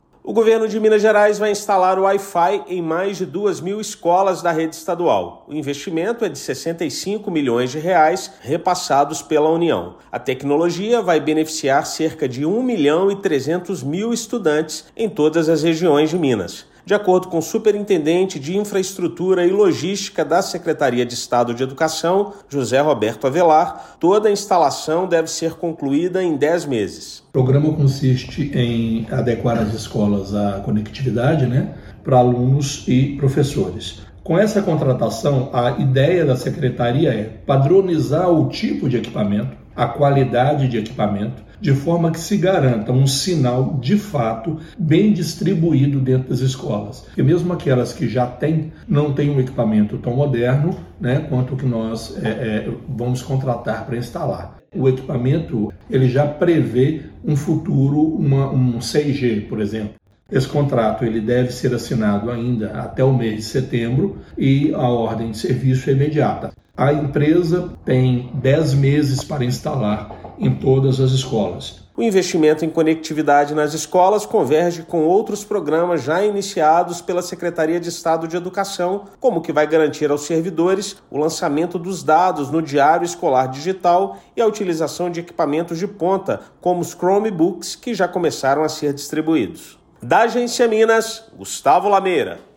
Com investimento de R$ 65 milhões, modernização da conectividade da rede estadual beneficiará cerca de 1,3 milhão de estudantes. Ouça matéria de rádio.